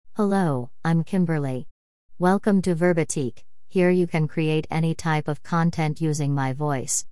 KimberlyFemale US English AI voice
Kimberly is a female AI voice for US English.
Voice sample
Listen to Kimberly's female US English voice.
Female
Kimberly delivers clear pronunciation with authentic US English intonation, making your content sound professionally produced.